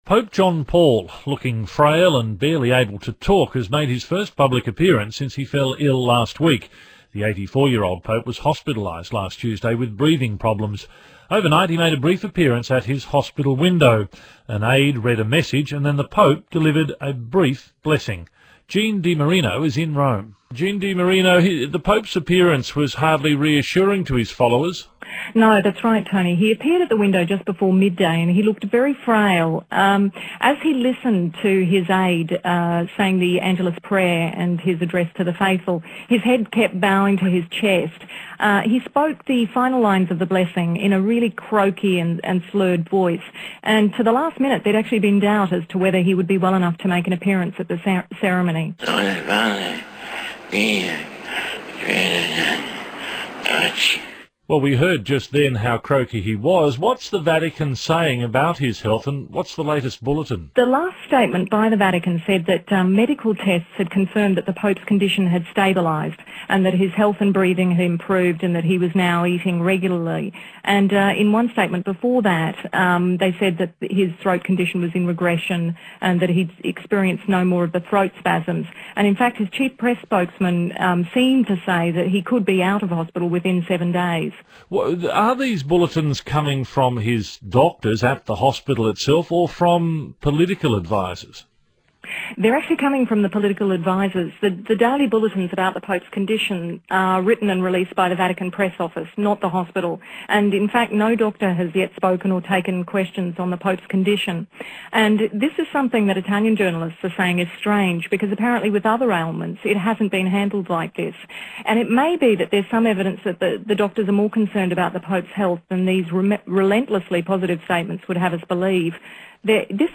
• Type Program: Radio
• Location: Rome, Italy
ABC Radio “AM”  two-way